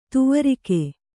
♪ tuvarike